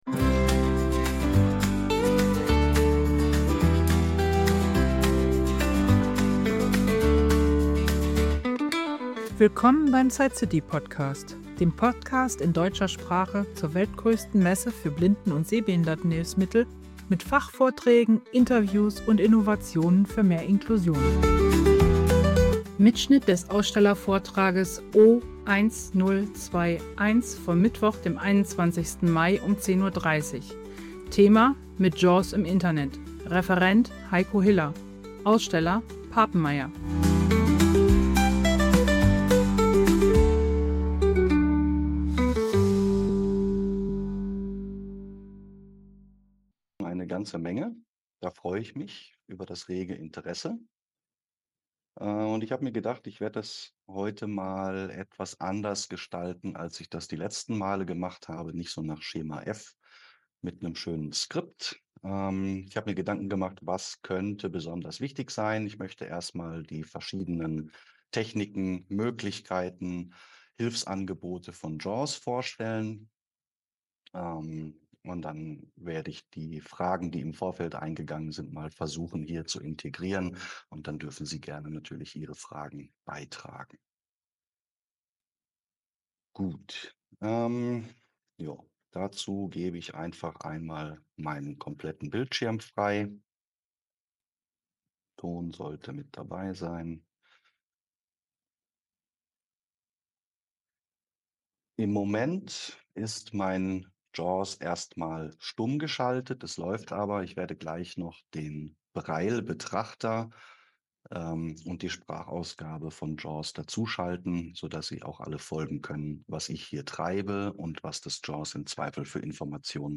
Vermittelt werden Strategien zur effizienten Nutzung von JAWS beim Surfen: Struktur verstehen, Schnellnavigation (Regionen, Überschriften, Formularfelder), Tabellen- und PDF-Bedienung sowie Umgang mit komplexen, werbe- oder cookieintensiven Seiten. Abschließend eine ausführliche Fragerunde mit praxisnahen Tipps.